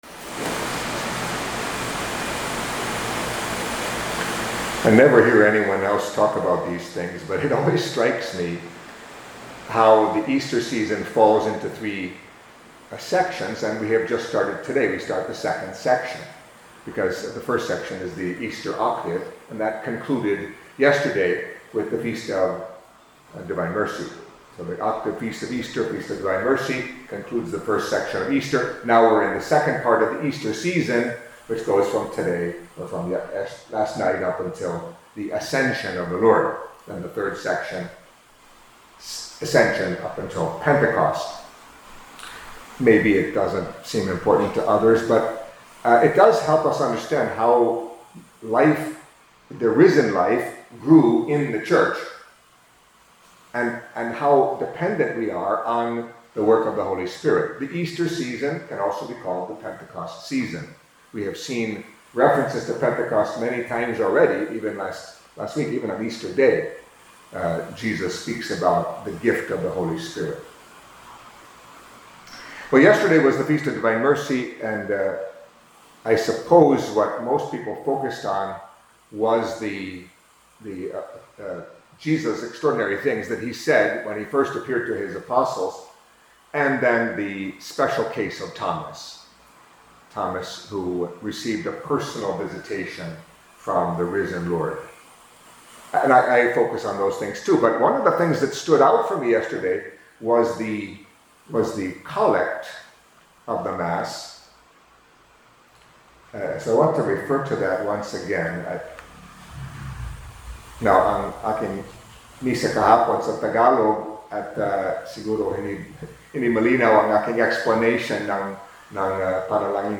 Catholic Mass homily for Monday in the Second Week of Easter